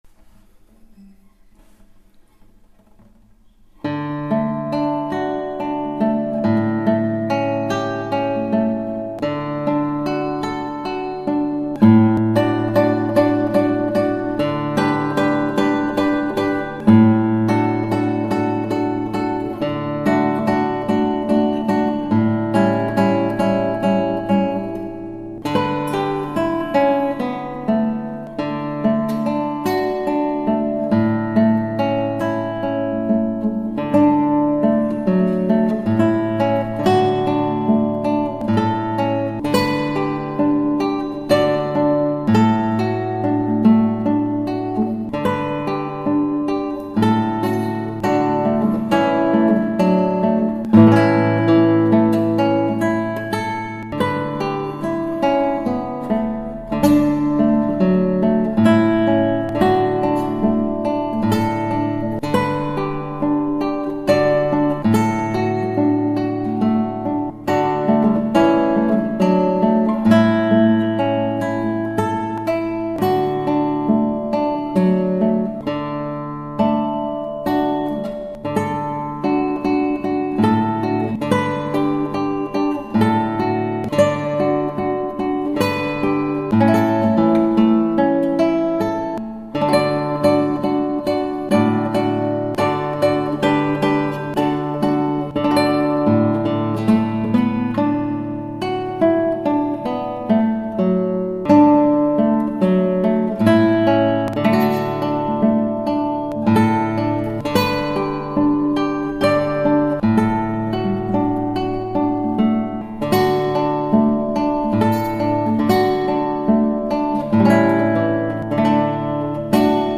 ギターは尾野ギター